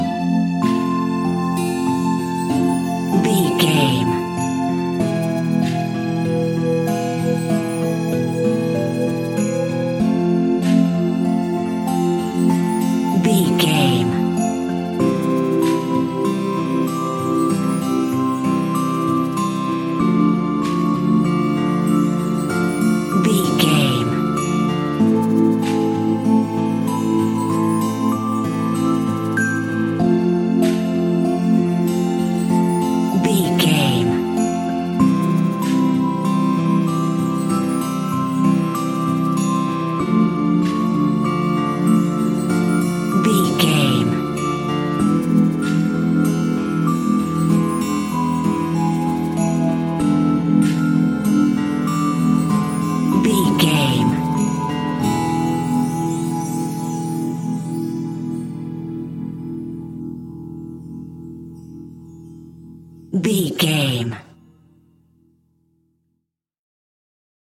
Aeolian/Minor
childrens music
instrumentals
fun
childlike
cute
happy
kids piano